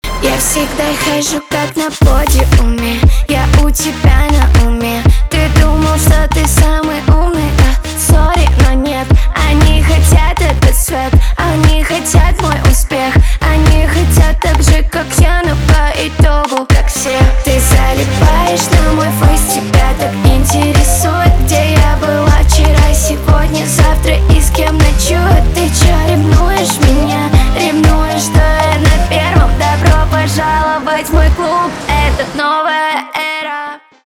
поп
басы